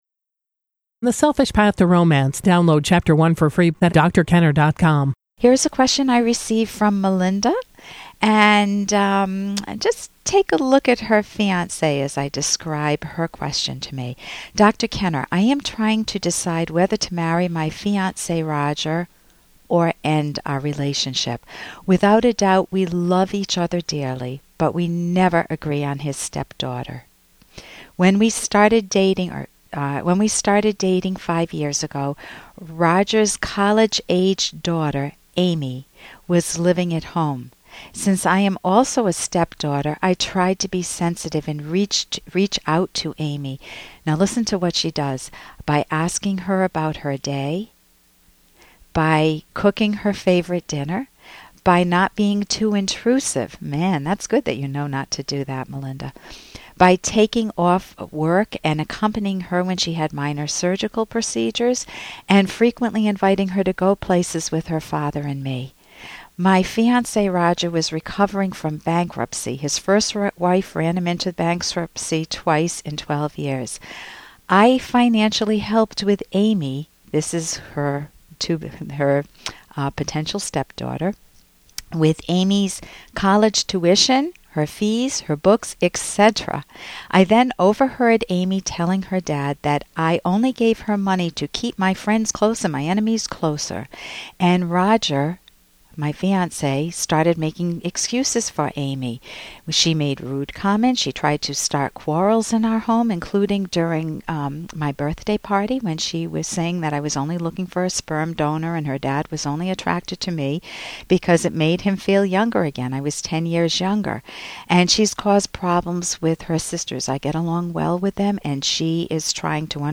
gives concise and practical advice to caller questions four times a week.